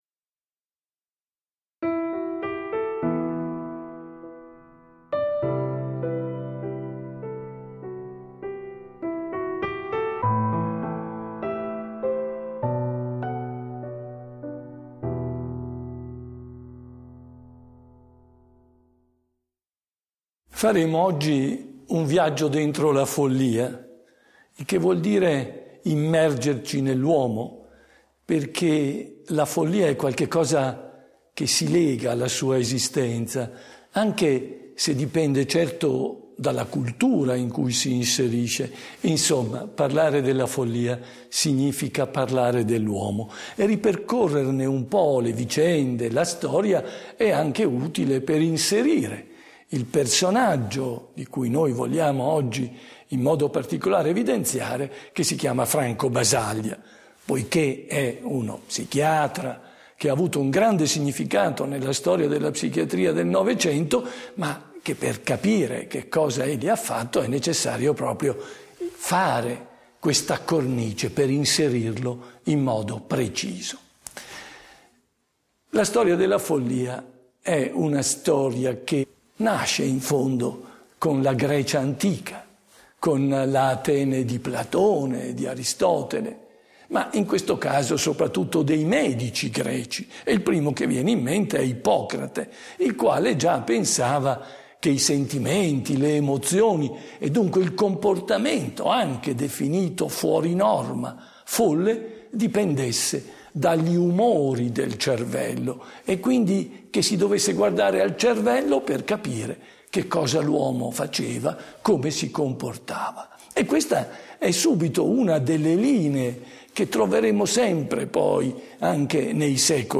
se ti interessa, ascolta o scarica l'audio della lezione di Vittorino Andreoli